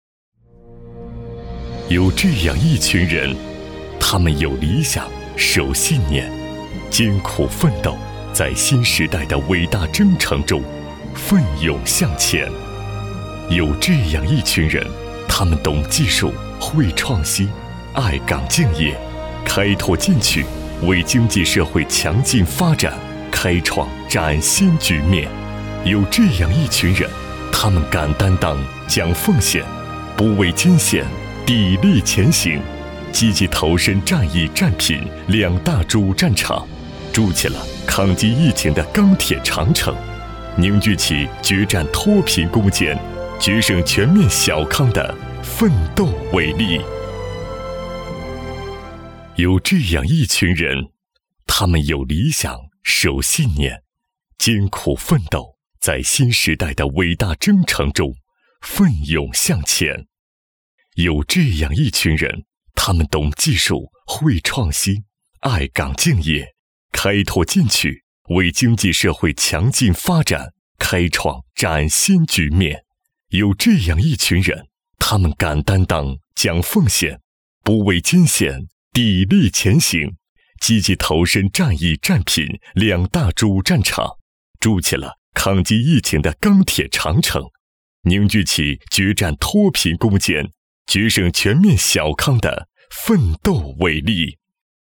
162男-大气恢宏
特点：大气浑厚 稳重磁性 激情力度 成熟厚重
风格:浑厚配音